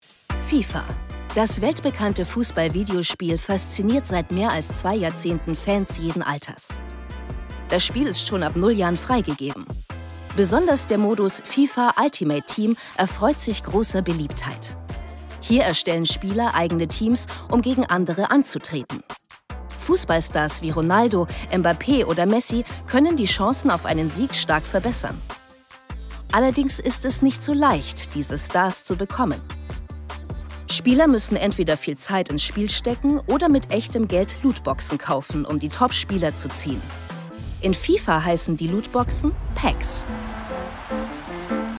markant, sehr variabel, hell, fein, zart
Jung (18-30)
Doku